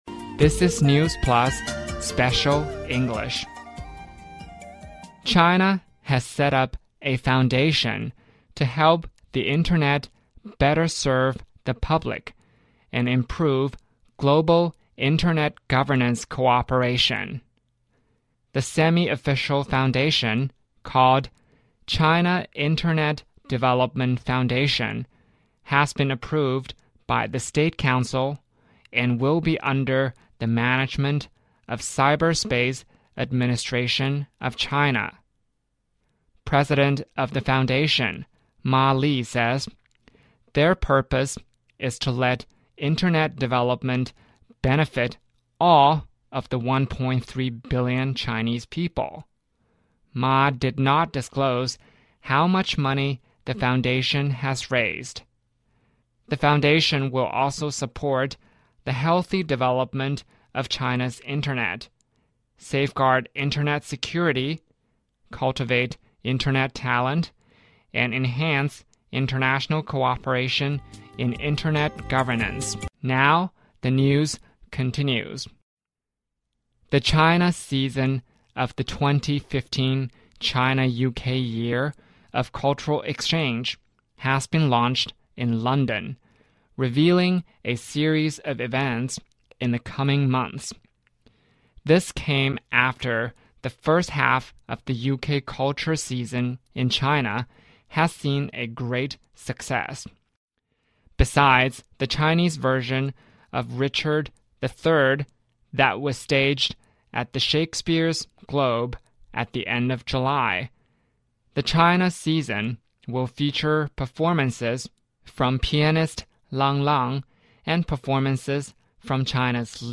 News Plus慢速英语:中国互联网发展基金会正式成立 2015中英文化交流年中国文化季启动